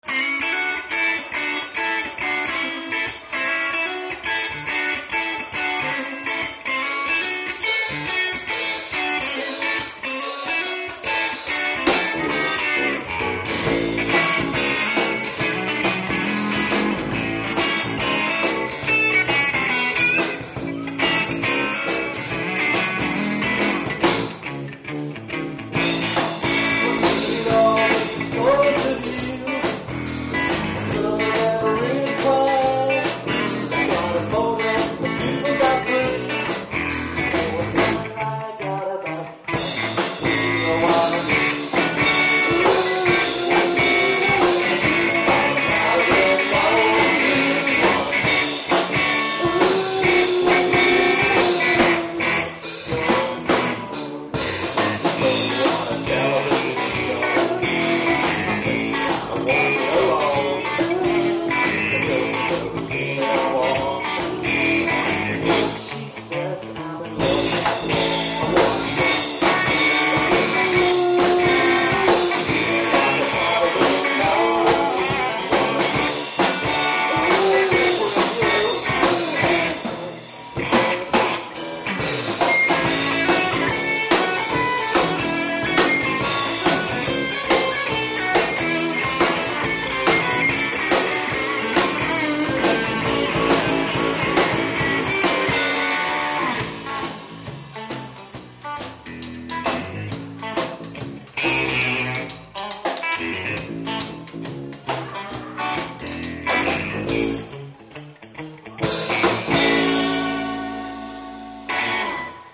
(Live)
MP3 Sample (Low Quality)